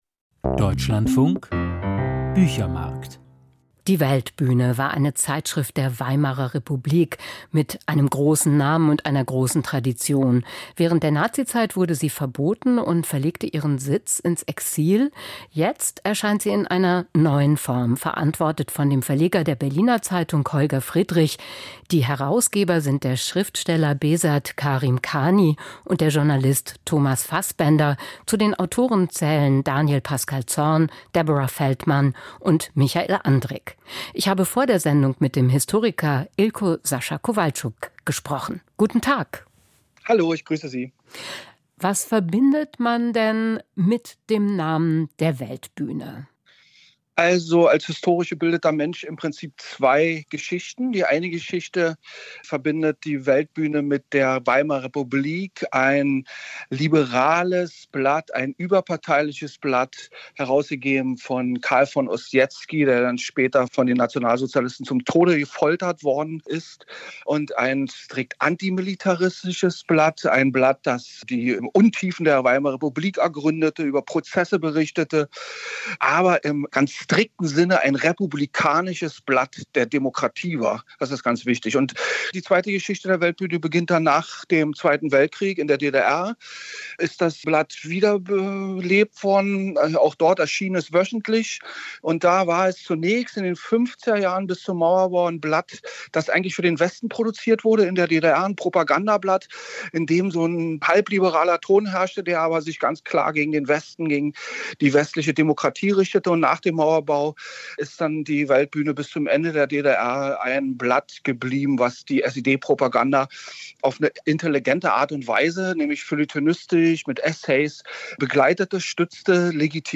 Zur Neuausgabe der "Weltbühne" - Ilko-Sascha Kowalczuk im Gespräch